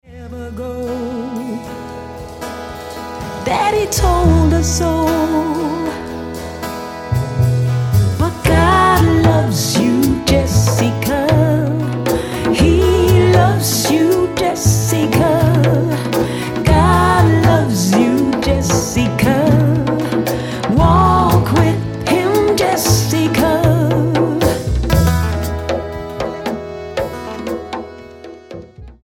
STYLE: Gospel